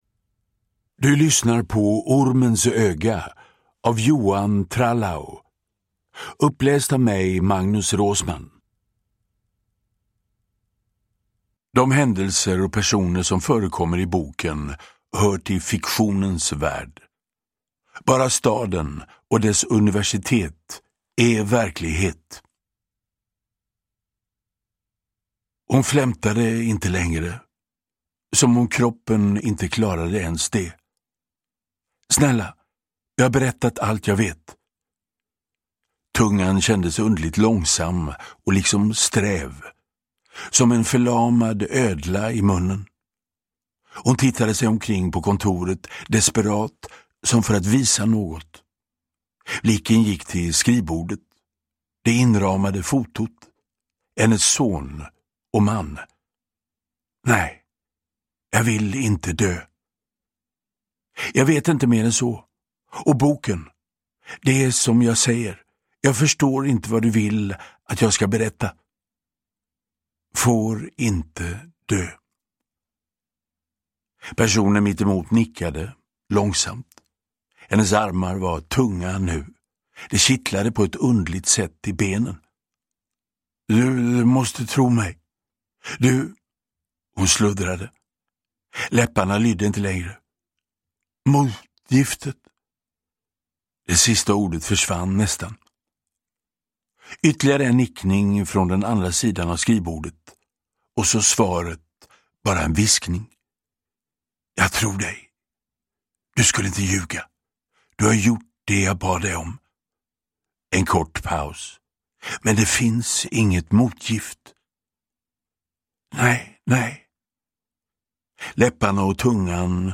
Uppläsare: Magnus Roosmann
Ljudbok